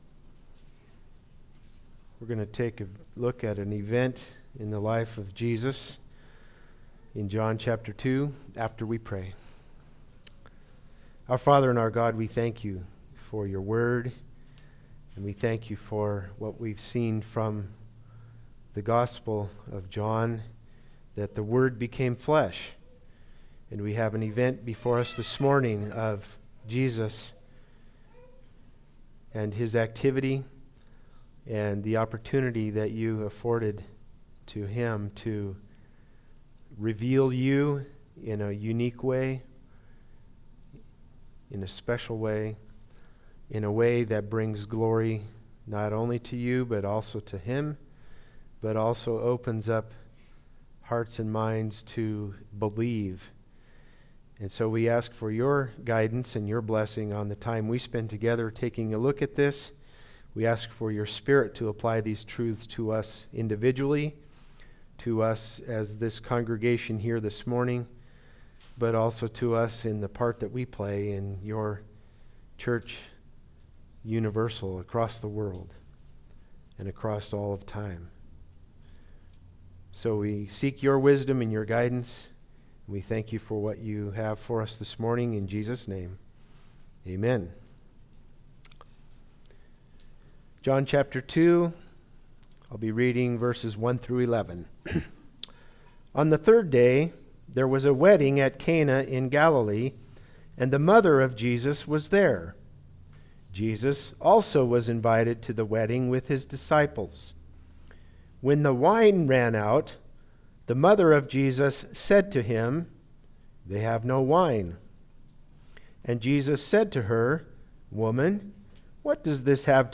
Passage: John 2:1-11 Service Type: Sunday Service